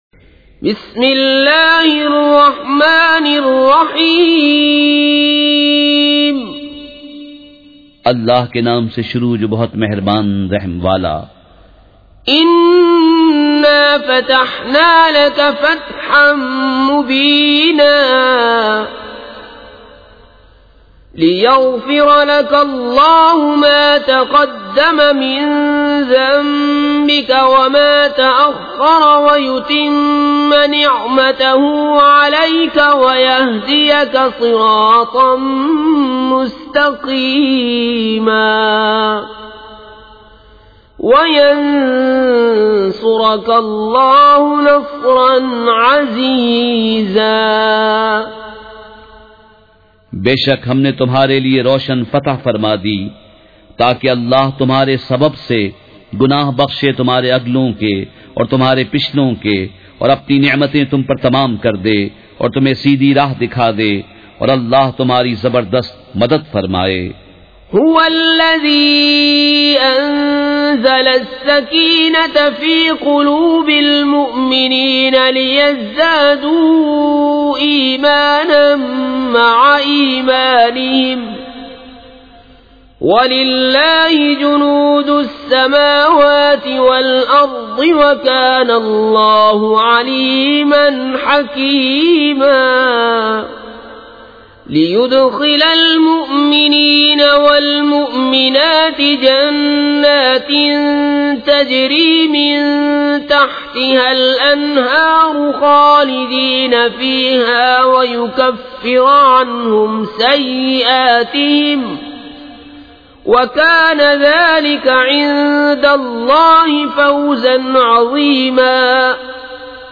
سورۃ الفتح مع ترجمہ کنزالایمان ZiaeTaiba Audio میڈیا کی معلومات نام سورۃ الفتح مع ترجمہ کنزالایمان موضوع تلاوت آواز دیگر زبان عربی کل نتائج 1896 قسم آڈیو ڈاؤن لوڈ MP 3 ڈاؤن لوڈ MP 4 متعلقہ تجویزوآراء